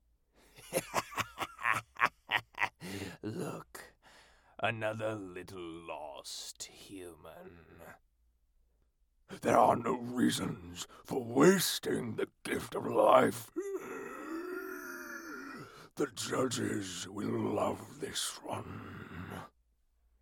Charismatic male VA with voice smoother than a glass of Pendleton on the rocks.
Creature Sample
Creature Sample.mp3